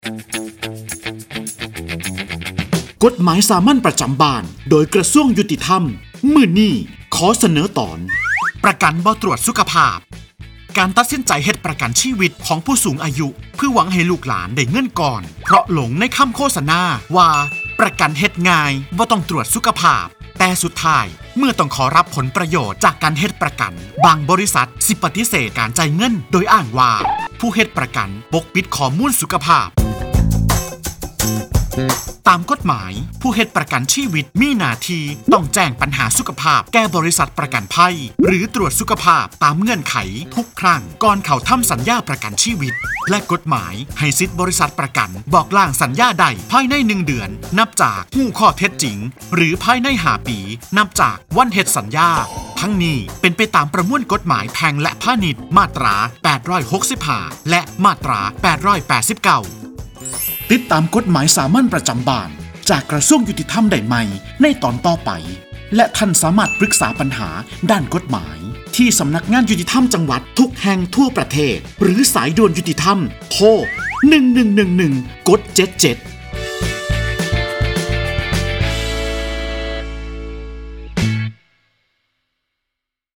กฎหมายสามัญประจำบ้าน ฉบับภาษาท้องถิ่น ภาคอีสาน ตอนประกันไม่ตรวจสุขภาพ
ลักษณะของสื่อ :   บรรยาย, คลิปเสียง